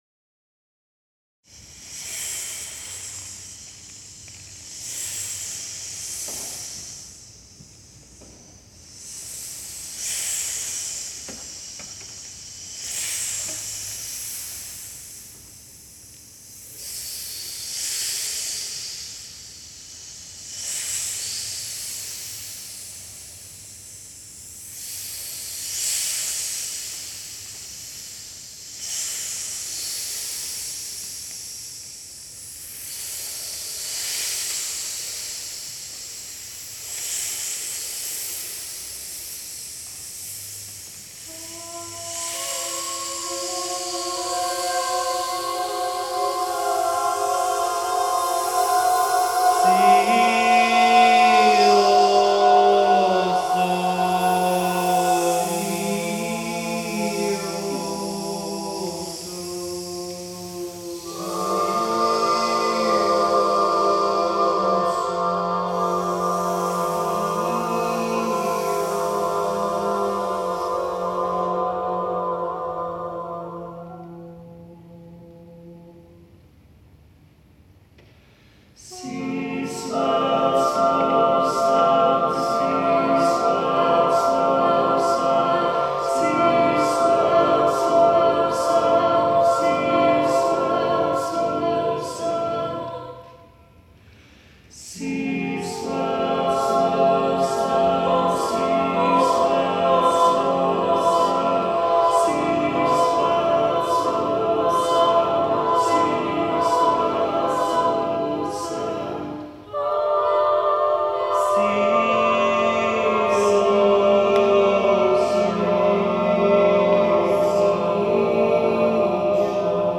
choral singing
mesmeric
in the Unitarian church on Stephen’s Green where we rehearse